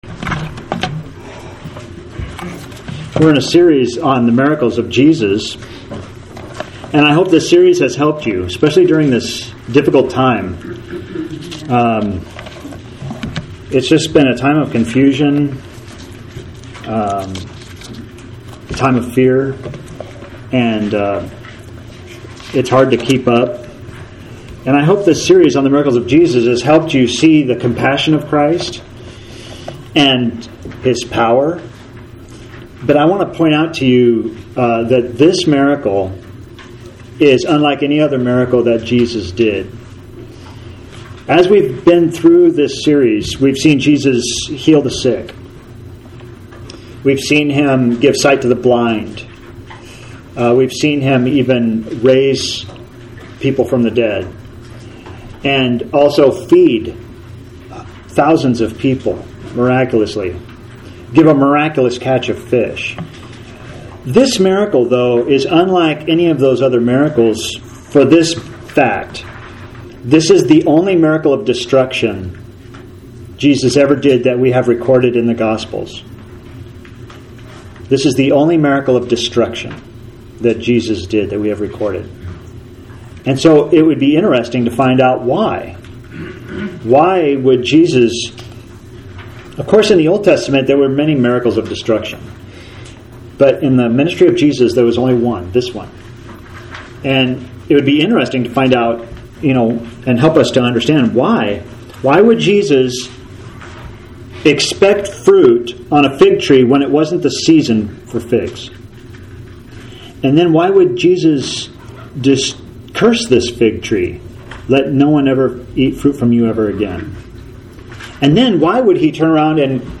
miracles23-live1.mp3